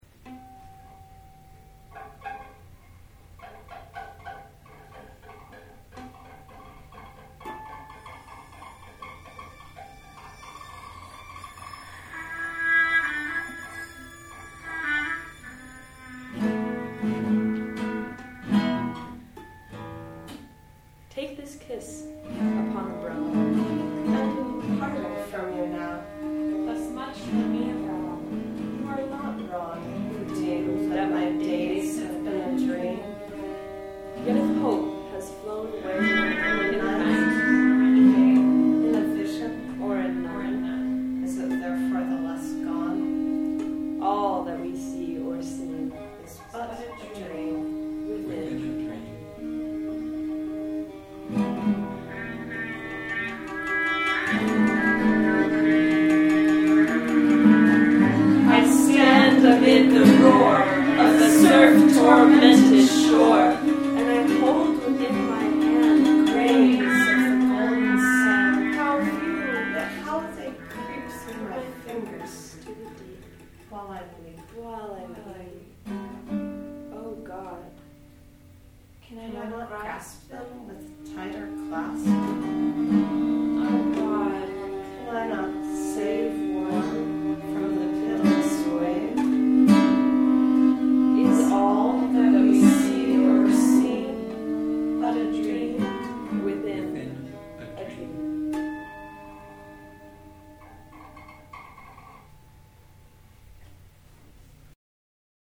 These files haven’t been mastered yet, so there’s some peaking and a few volume problems, but if you want to get a quick-and-dirty MP3 sense of what we recorded yesterday, have a listen.